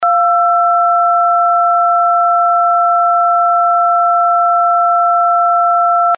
音效
电报.mp3